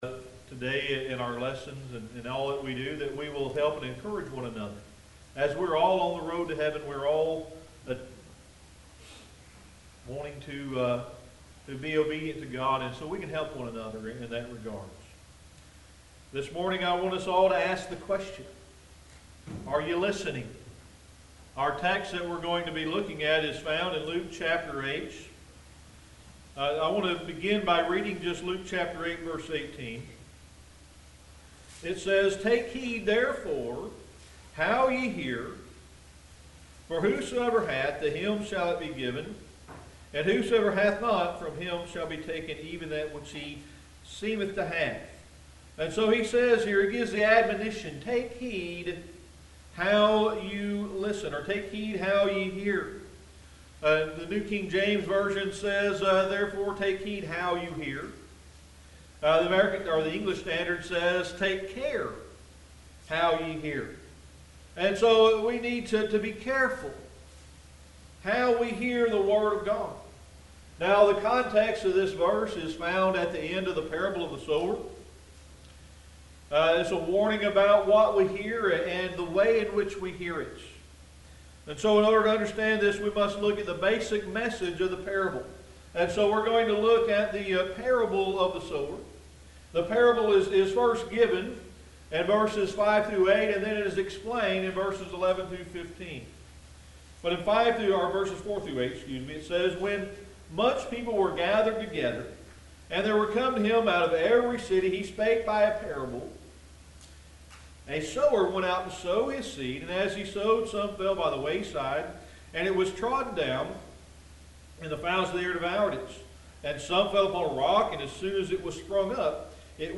Sermon: Are You Listening?